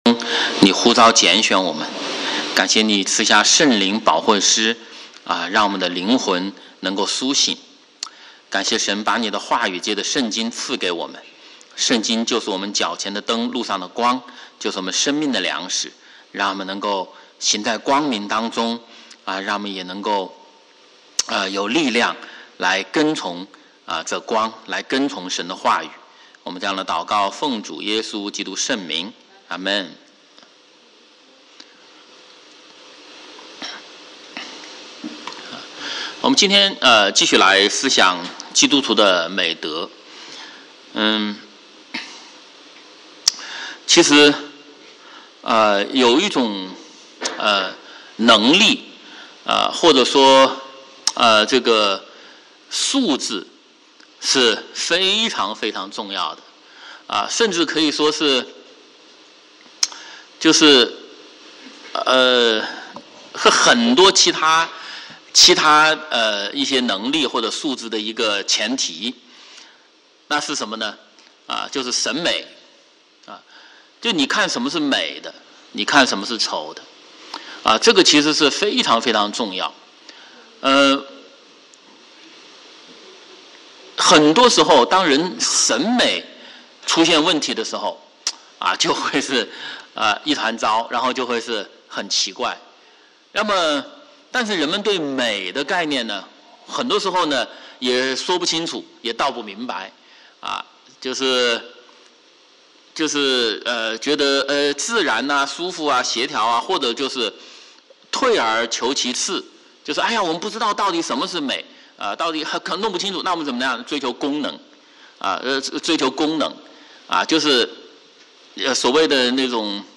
Adult Sunday school:Sundays @ 9:30am